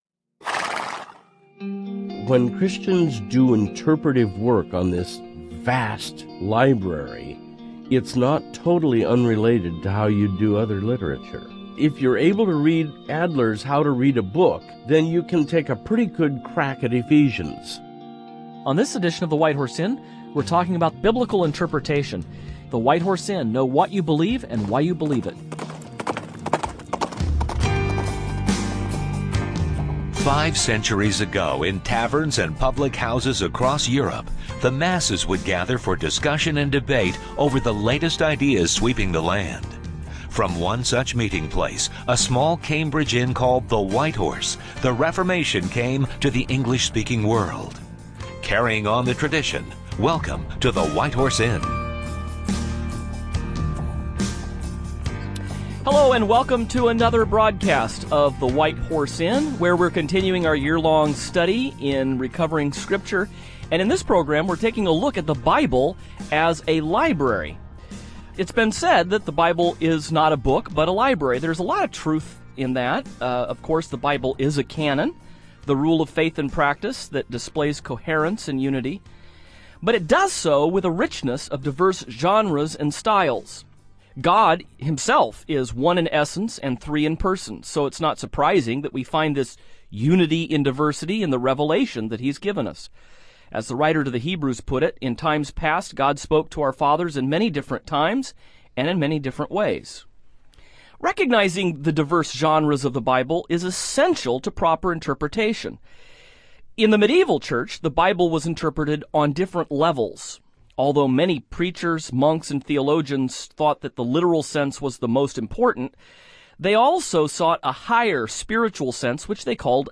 On this edition of the program the hosts take up the subject of hermeneutics, or proper biblical interpretation.